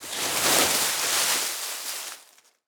FreeGardenSFX_RakeLeaves1.wav